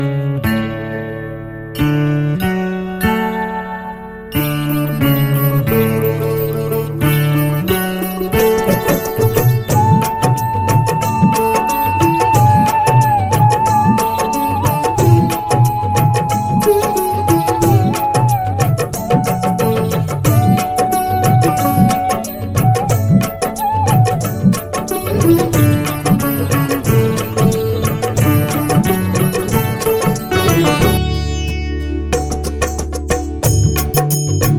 Karaoke Version Mp3 Track For Demo